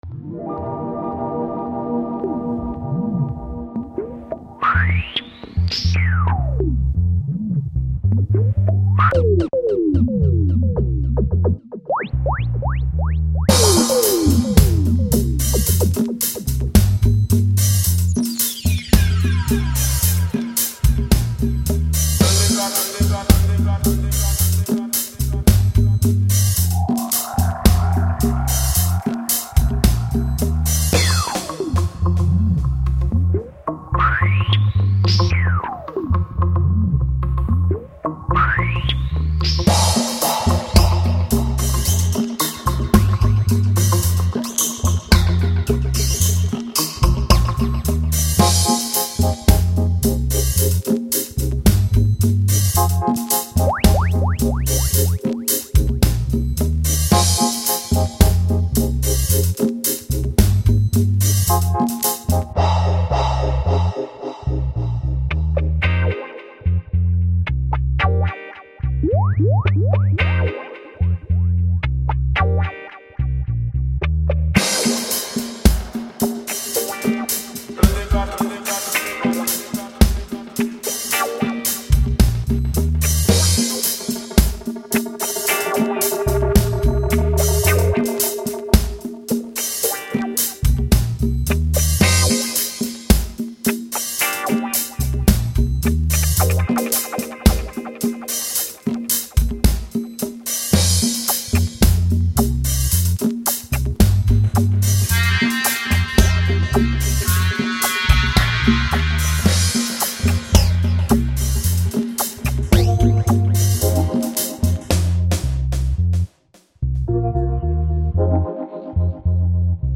Super fine dub .
Tagged as: Electronica, World, Reggae, Dub